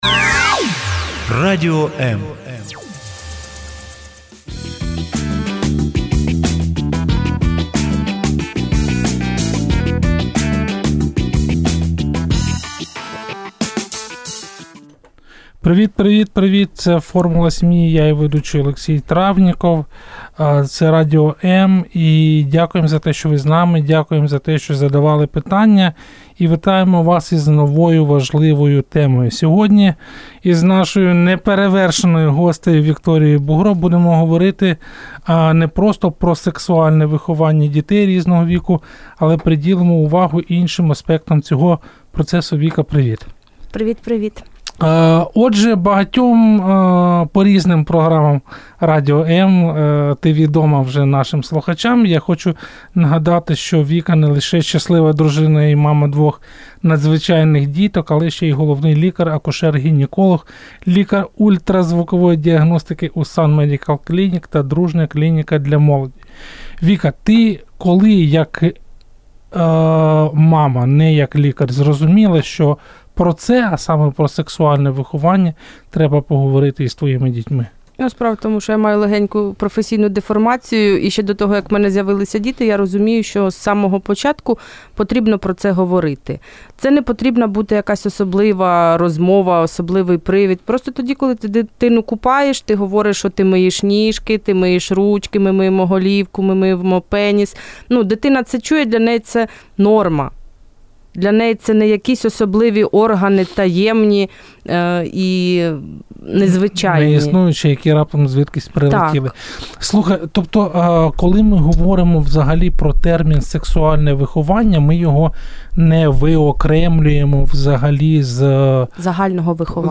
Завантажати запис ефіру